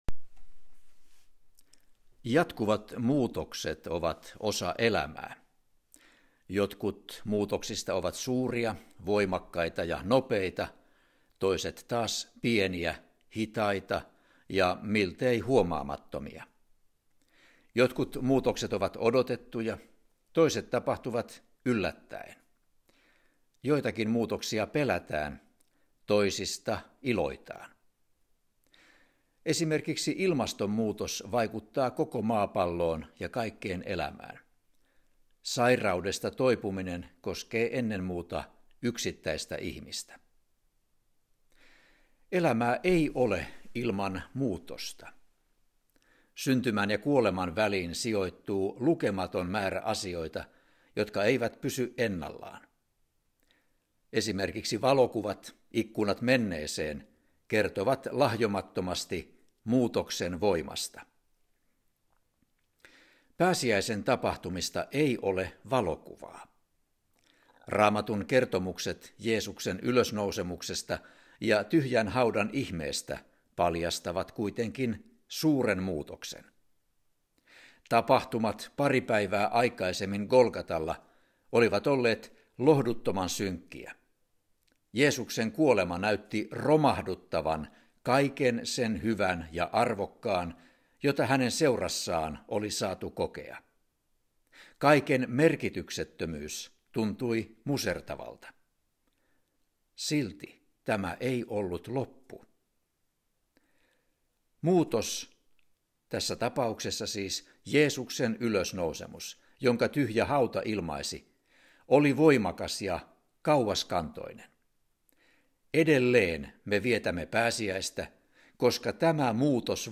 – Muutos, Jeesuksen ylösnousemus, jonka tyhjä hauta ilmaisi, oli voimakas ja kauaskantoinen. Edelleen vietämme pääsiäistä, koska tämä muutos vaikuttaa tässä maailmassa, sanoo arkkipiispa Tapio Luoma pääsiäistervehdyksessään.